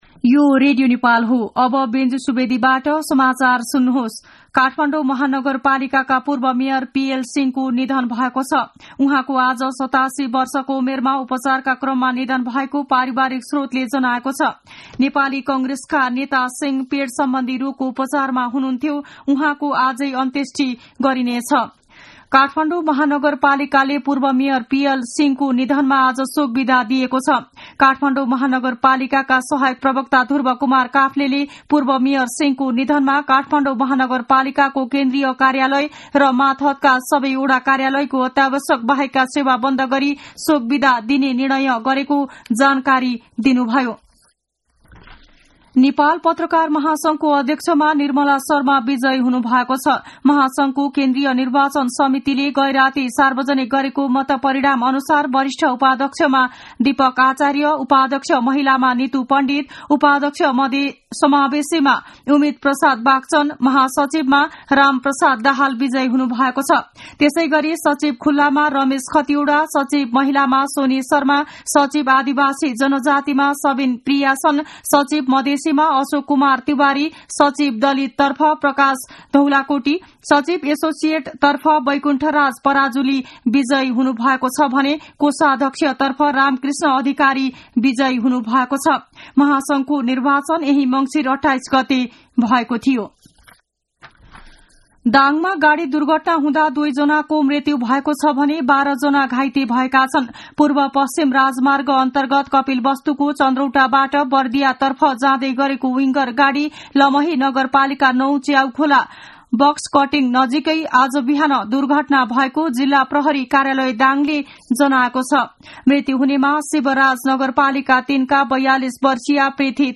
मध्यान्ह १२ बजेको नेपाली समाचार : २ पुष , २०८१
12-am-nepali-news-1-12.mp3